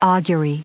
added pronounciation and merriam webster audio
434_augury.ogg